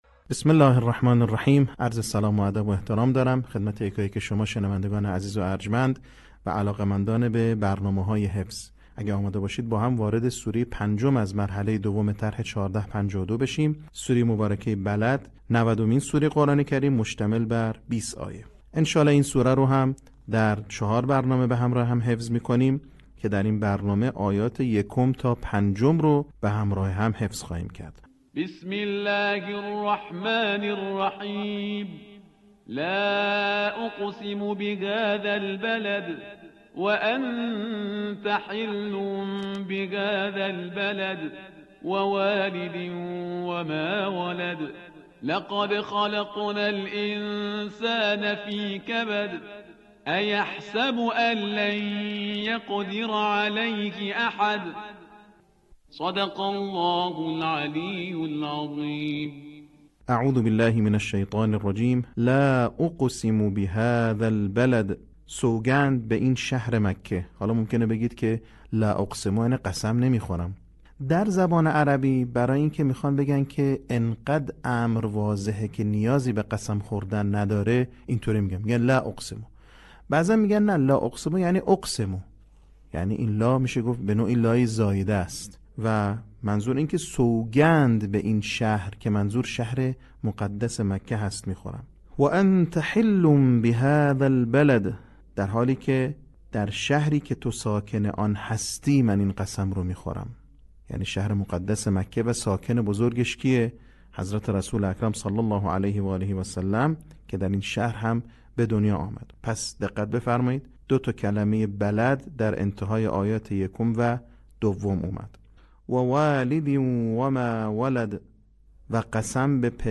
صوت | آموزش حفظ سوره بلد